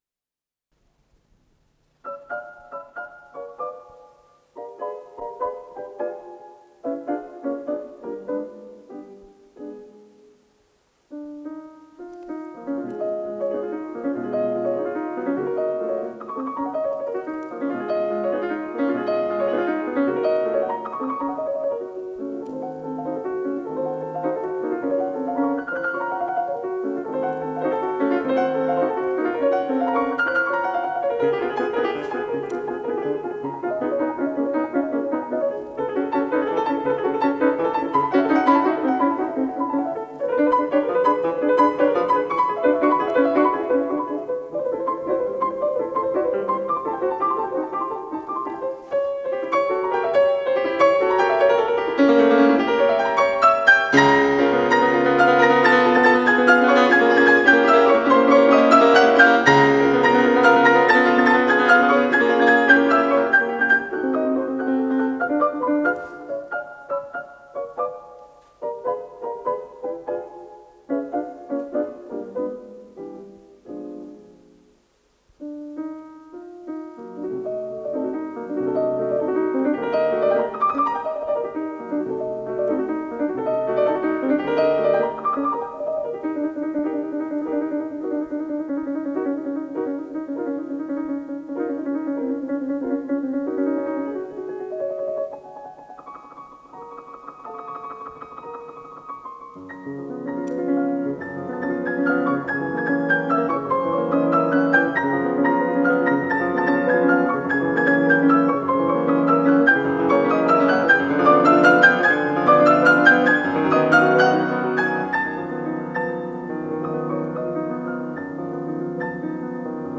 с профессиональной магнитной ленты
ПодзаголовокФа диез мажор
ВариантДубль моно